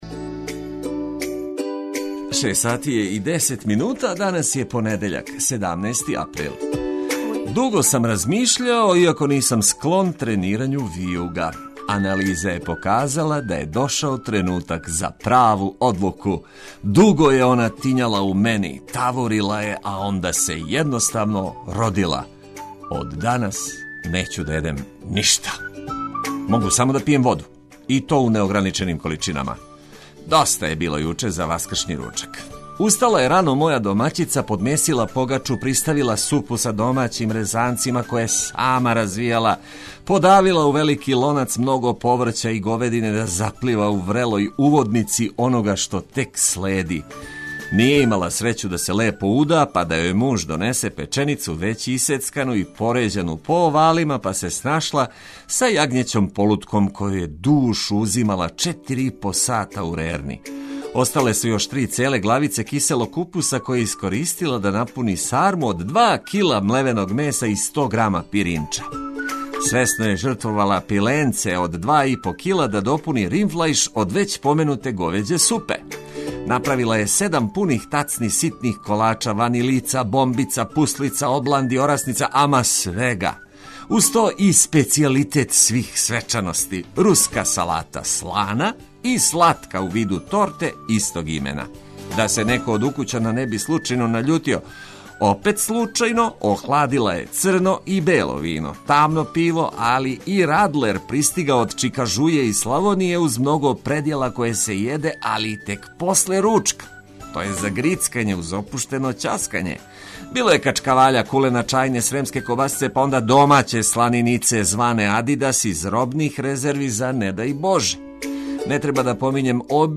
Други дан Васкрса обележићемо ведром музиком коју ћемо оплеменити лепим и корисним вестима.